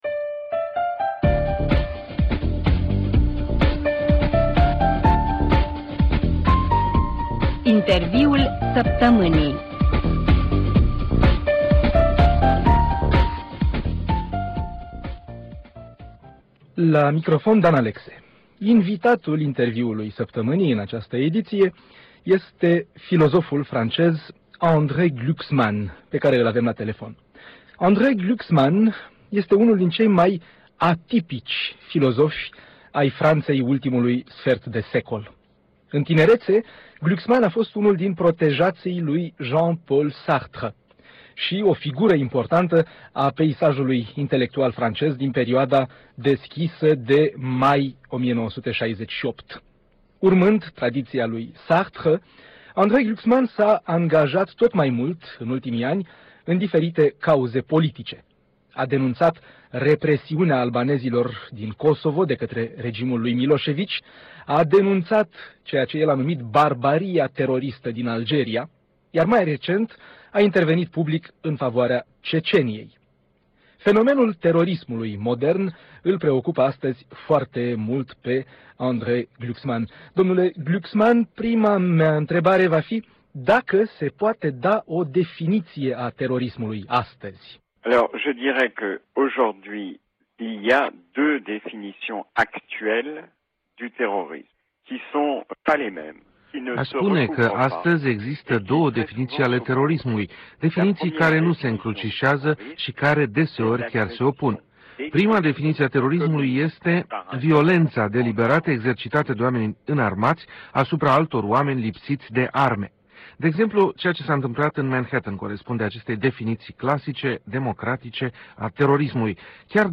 Un interviu în exclusivitate acordat Europei Libere.